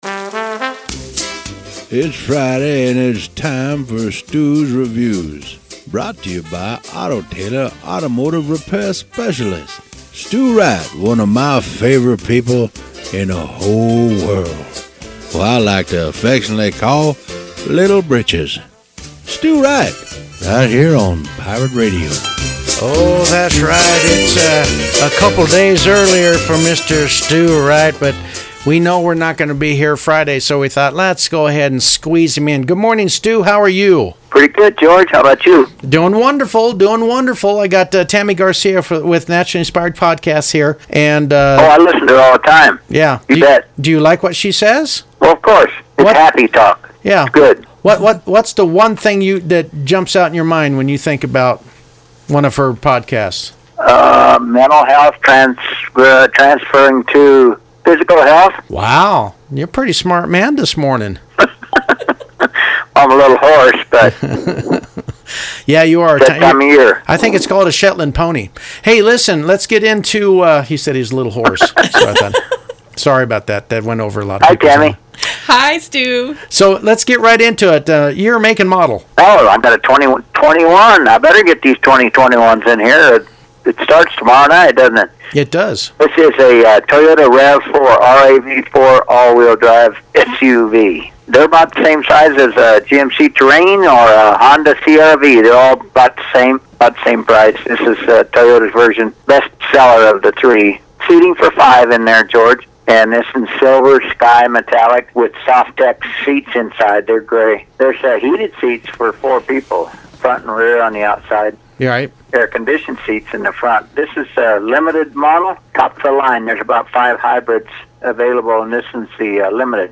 Radio review at Pirate Radio